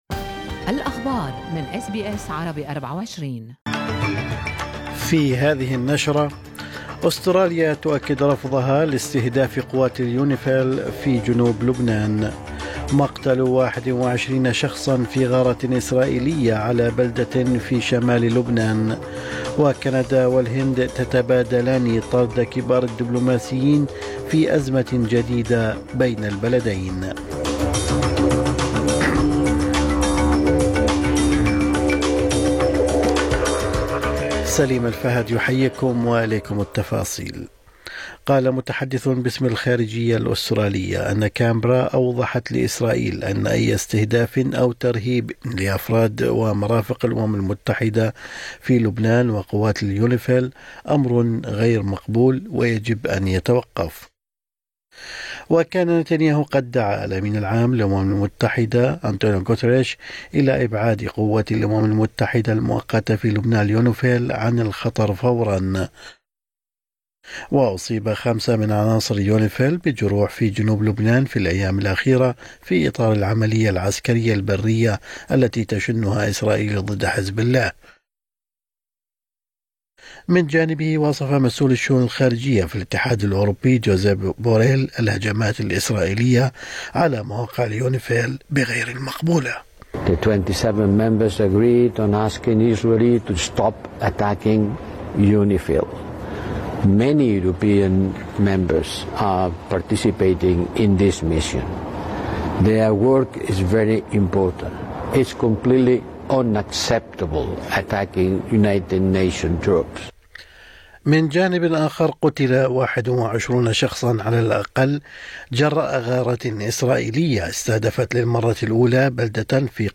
نشرة أخبار الصباح 15/10/2024